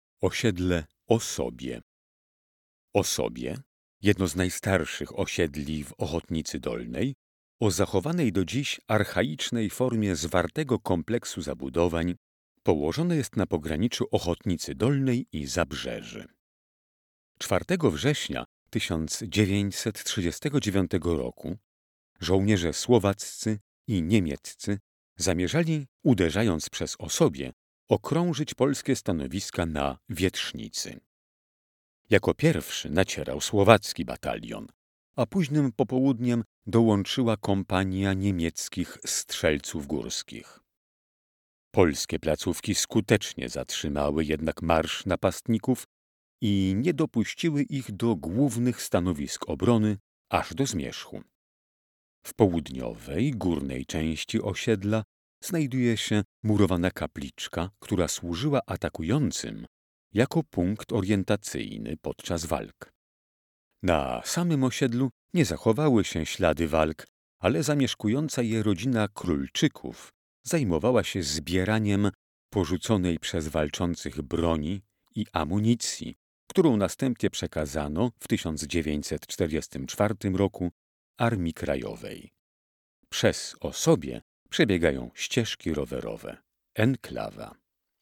Opis miejsca w wersji audio